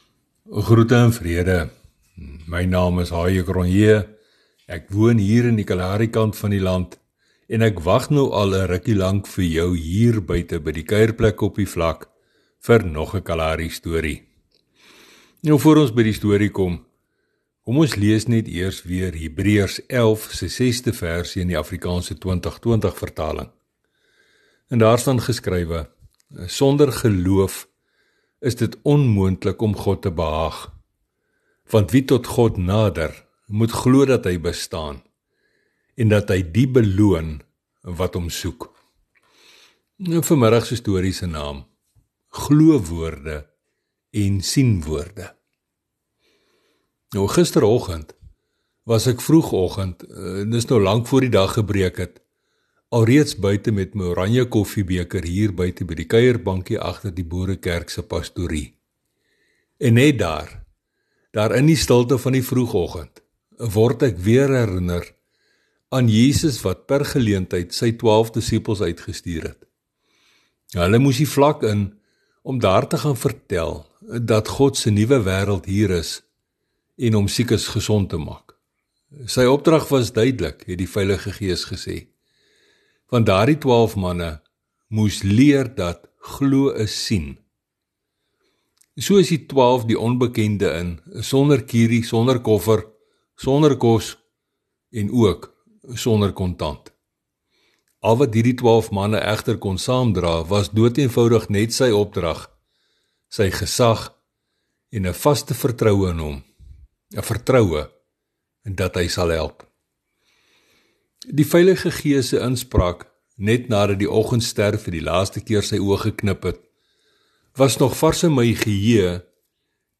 Hy vertel vandag vir ons nog een van sy Kalaharistories. Sy getuienis verhale het 'n geestelike boodskap, maar word vertel in daardie unieke styl wat mens slegs daar op die kaal vlaktes kan optel.